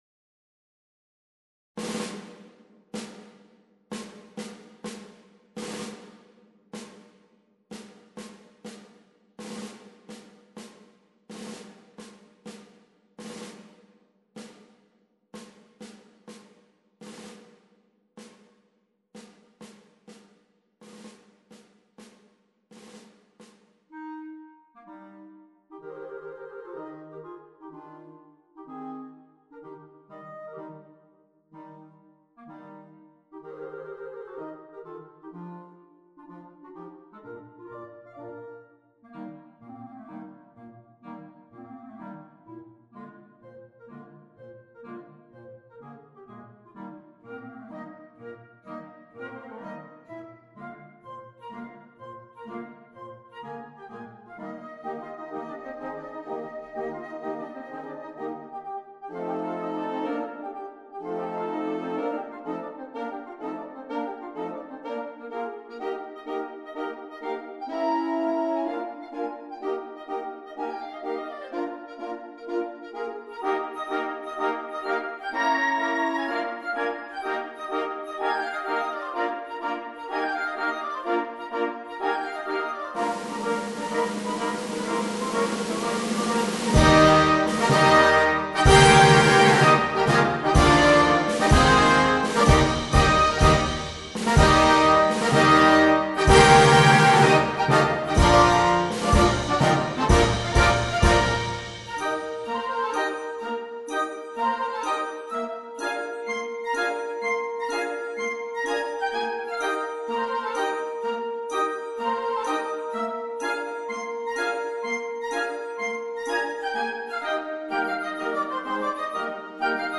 La trascrizione per banda